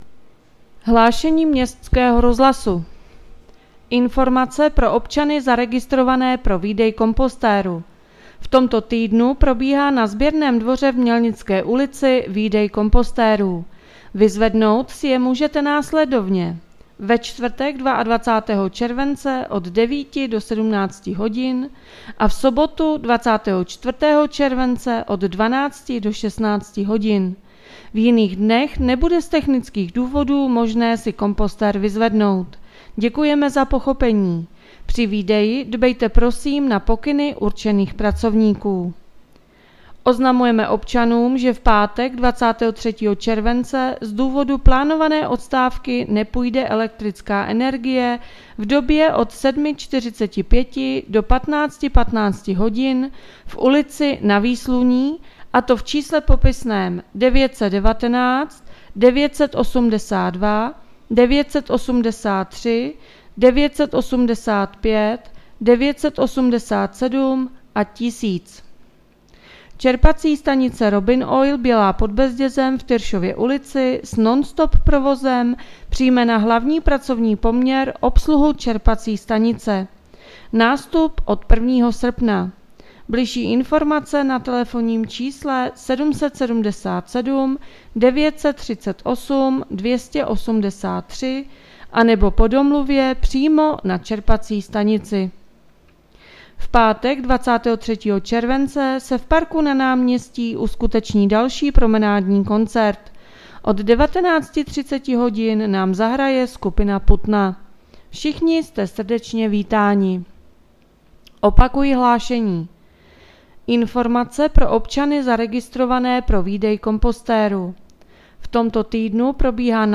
Hlášení městského rozhlasu 21.7.2021